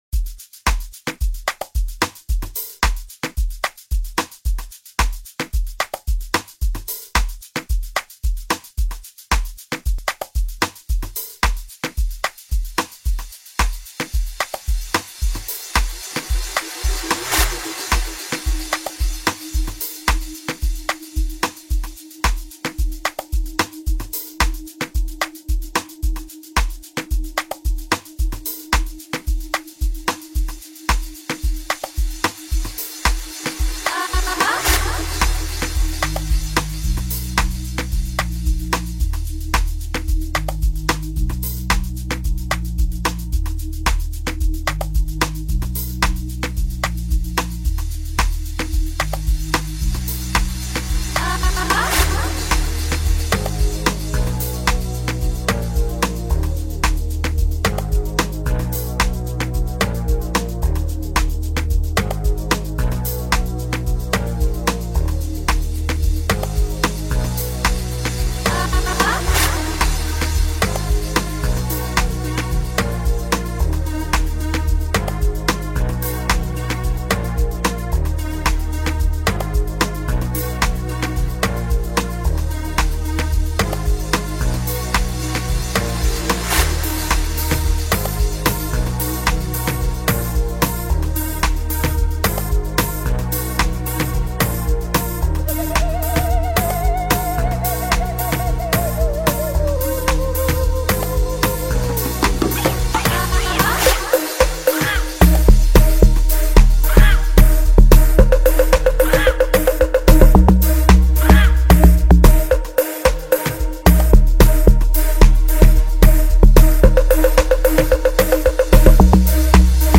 Amapiano
amapiano song